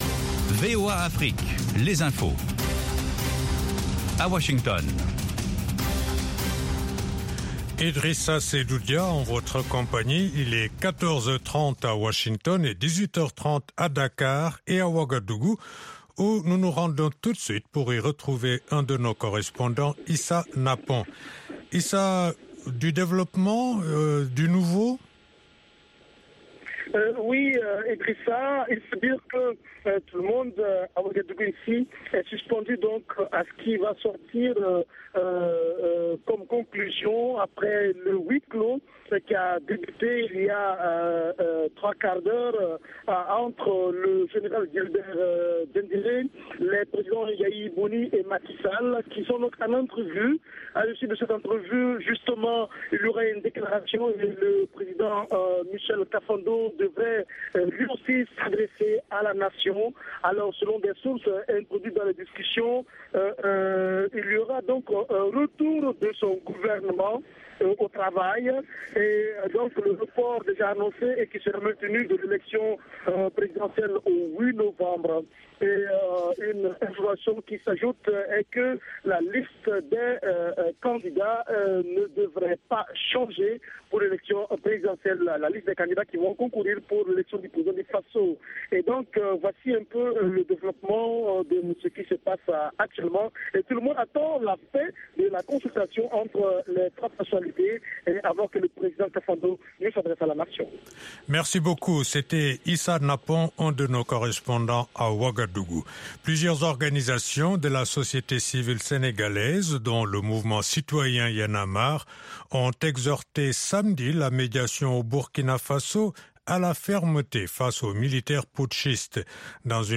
Bulletin
5 Min Newscast